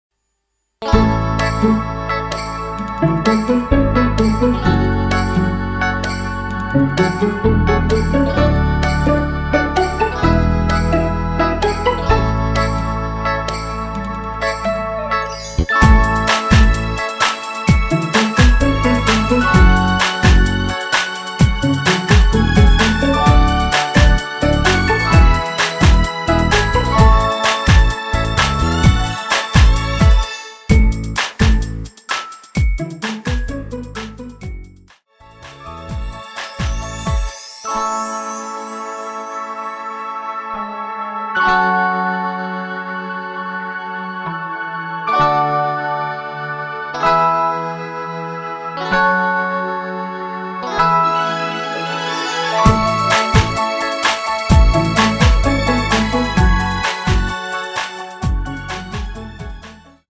여자키에서 (-2) 더 내린 MR 입니다.
◈ 곡명 옆 (-1)은 반음 내림, (+1)은 반음 올림 입니다.
앞부분30초, 뒷부분30초씩 편집해서 올려 드리고 있습니다.
중간에 음이 끈어지고 다시 나오는 이유는